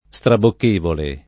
Strabokk%vole] agg. — nell’uso ant., anche istrabocchevole [iStrabokk%vole] pur dopo una vocale: a gran fatiche e a istrabocchevoli e non pensati pericoli [a ggr#n fat&ke e a iStrabokk%voli e nnom penS#ti per&koli] (Boccaccio)